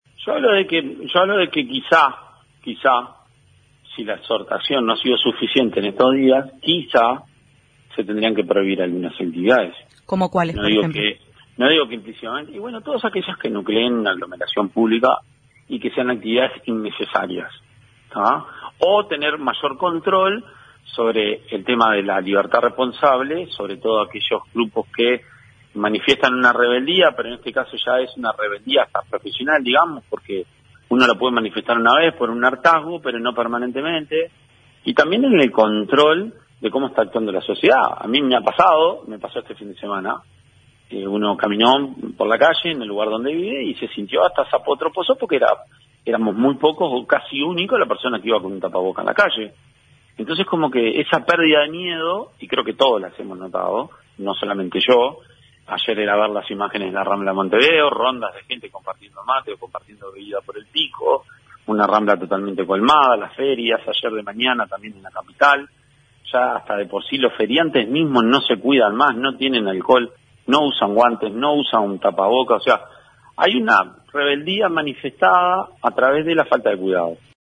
En diálogo con 970 Noticias dijo que se tendrían que prohibir algunas actividades.